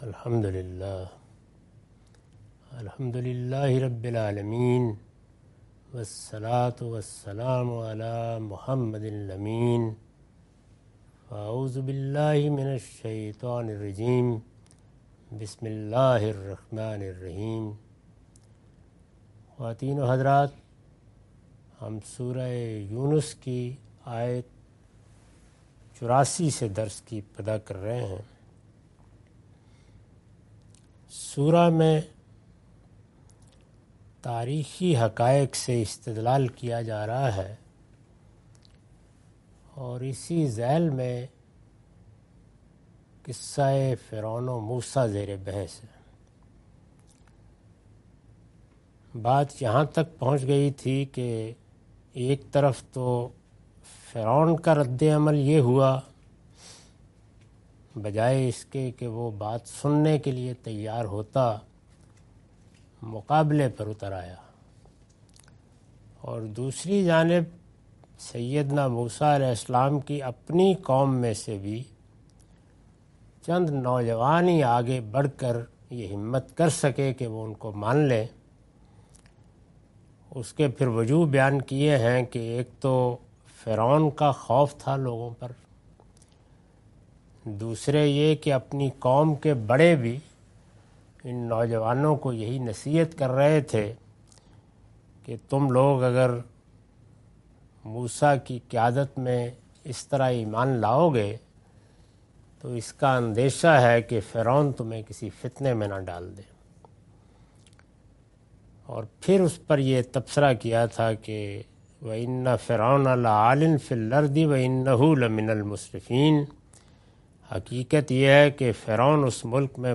Surah Yunus- A lecture of Tafseer-ul-Quran – Al-Bayan by Javed Ahmad Ghamidi. Commentary and explanation of verses 84-87.